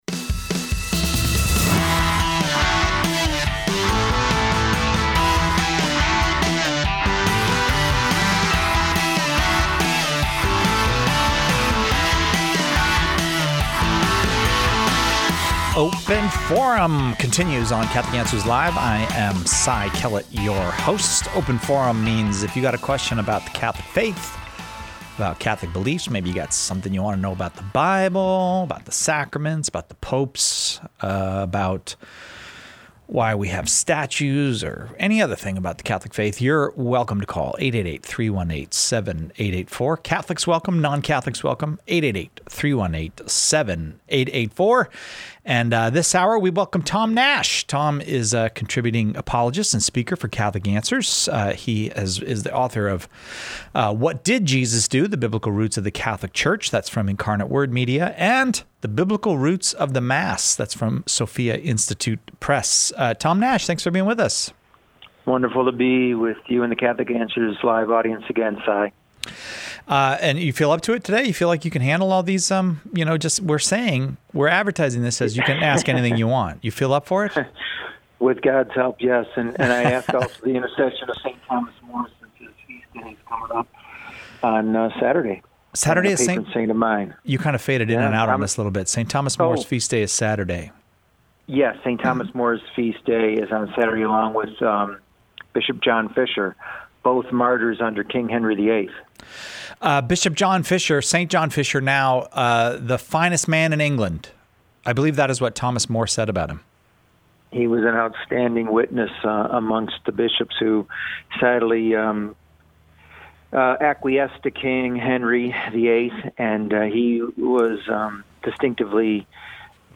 All questions about the Catholic faith and life are welcome during open forum.